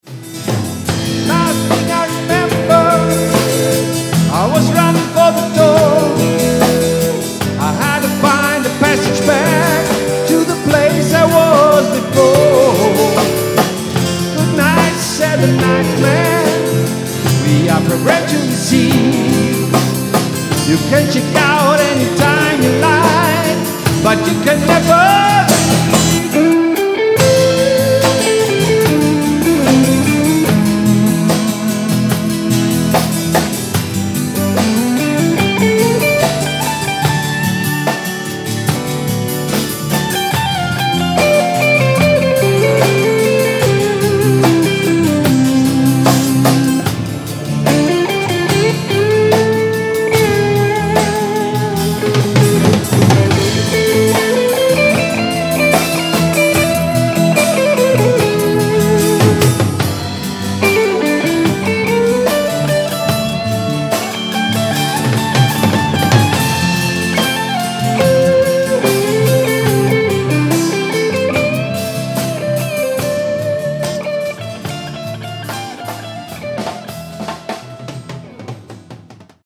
absolut Livemusic